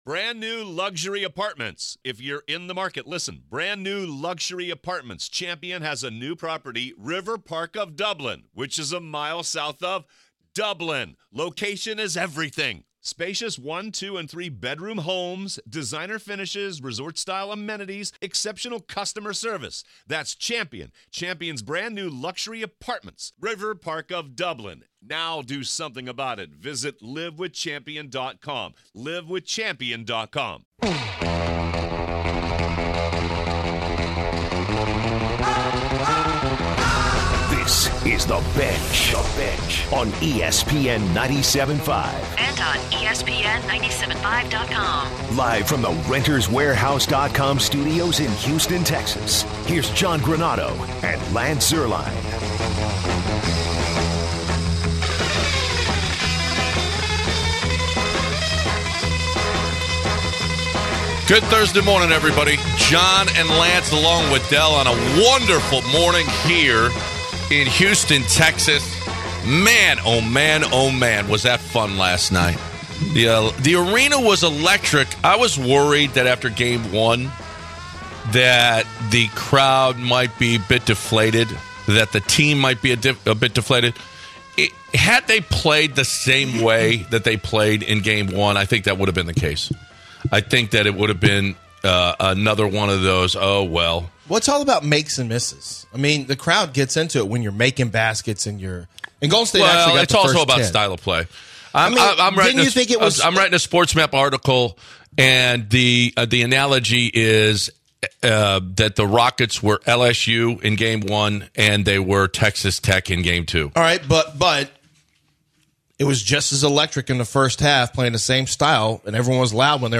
Ice Cube calls into the show to comment on Rockets in the series and the changes made to the Big 3 league for year two. They end the hour talking about the Western Conference series.